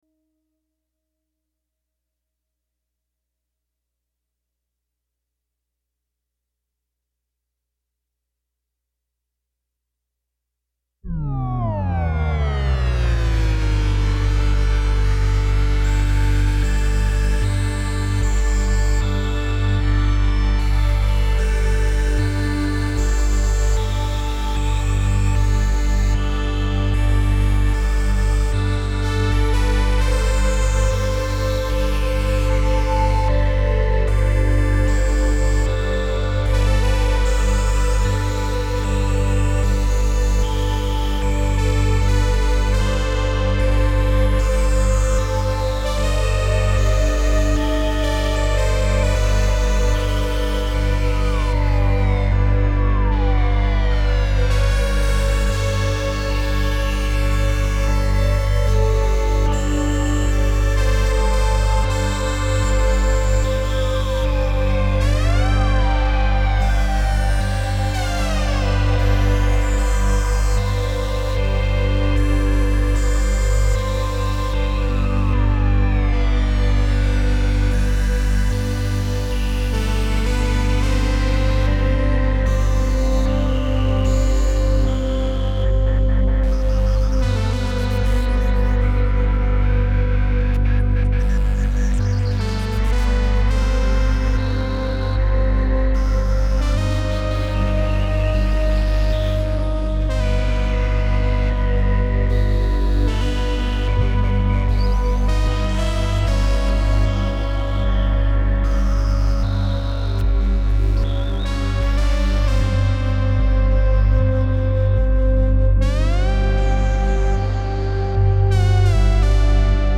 It’s happy!
This piece is perhaps too Anthemic for Yoga though.
I thought I’d play off that line and do a Vangelis type piece.
The piece runs about 4 minutes and is vamped. Meaning, I made it up in one pass without editing. By the way, the melody is played on my trusty Minimoog. All the swirly real time stuff is the Korg Z1.